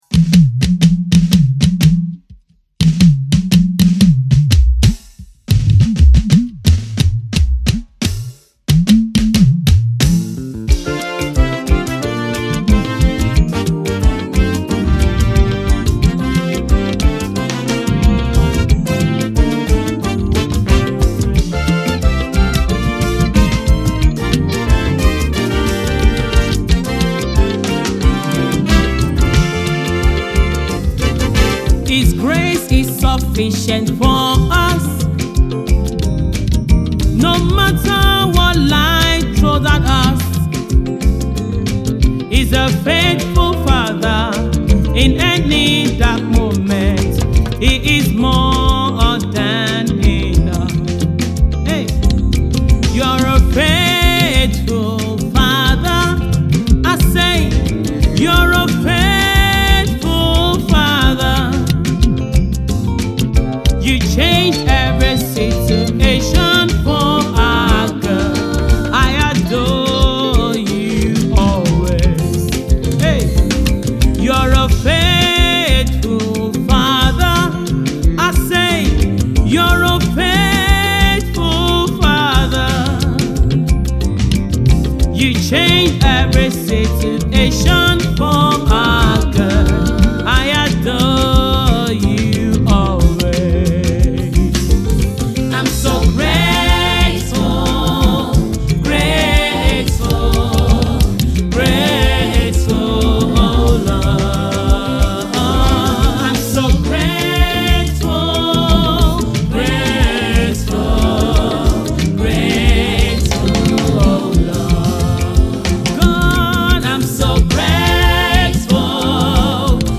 Thanksgiving track
Gospel singer